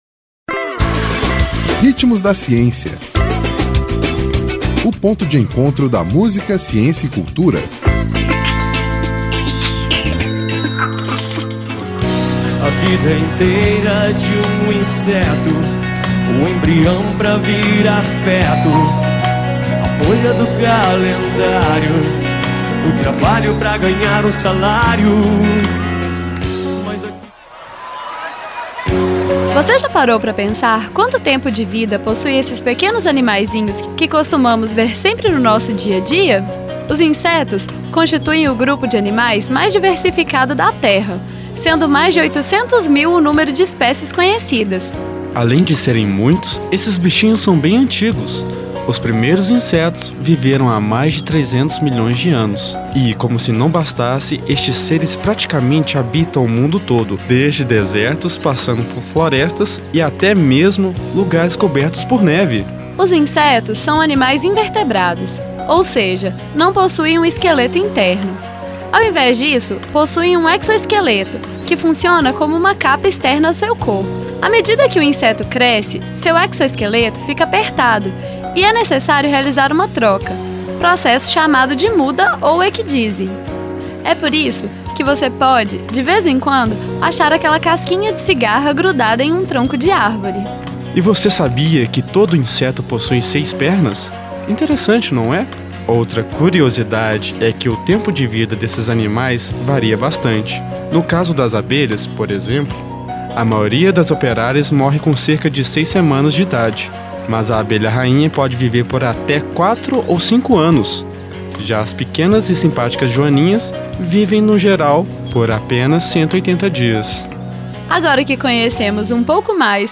Vozes